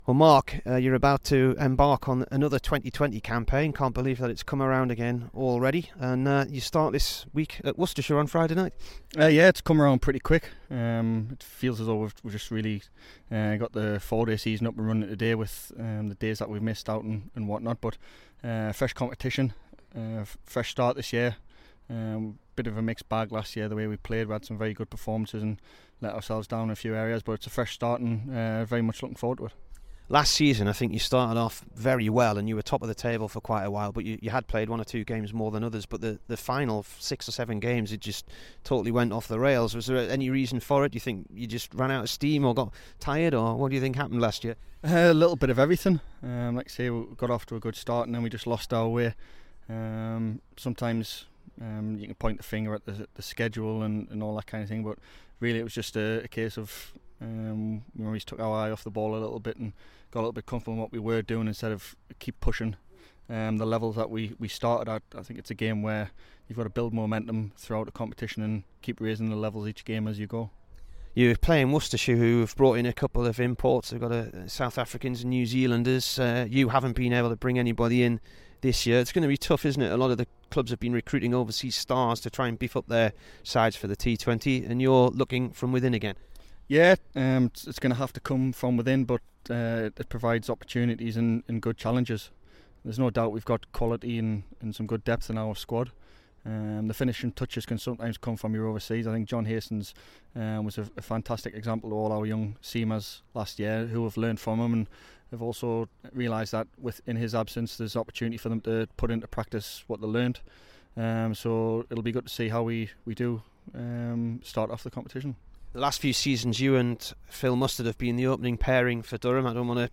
Durham one day captain Mark Stoneman spoke to BBC Newcastle ahead of the start of the T20 Blast.